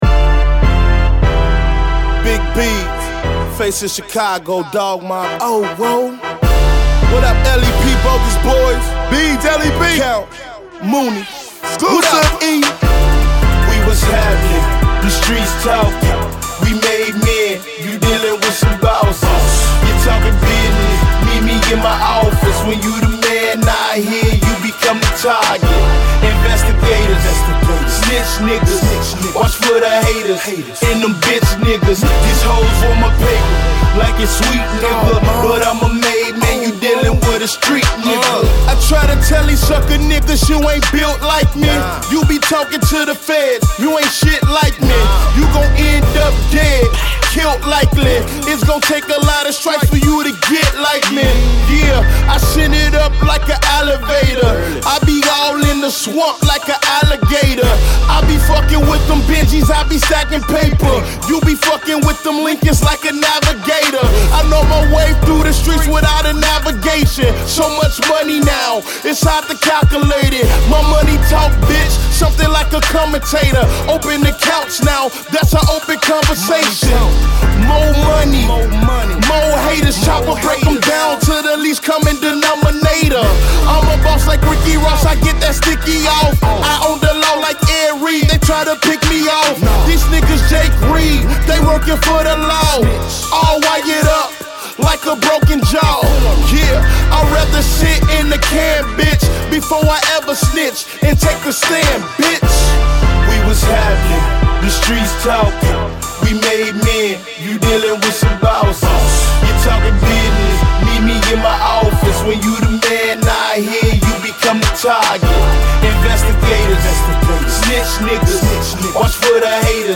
Chicago emcee